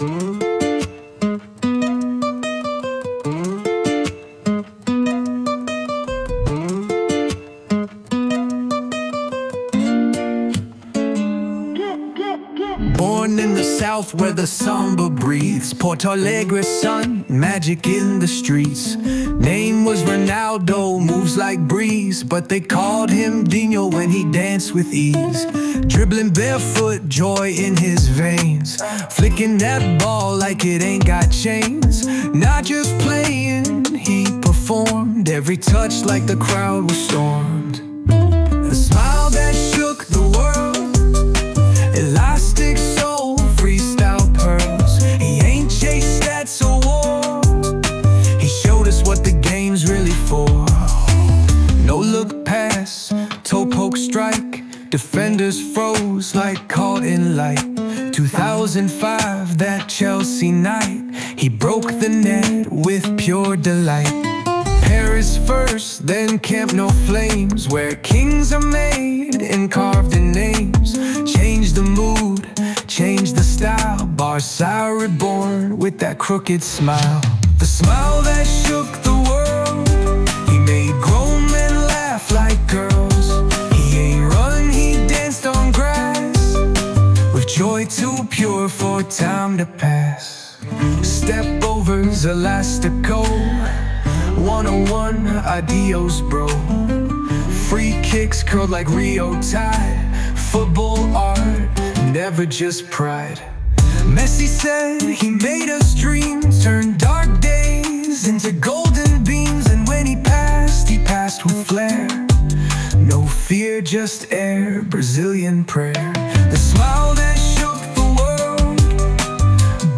original rap tribute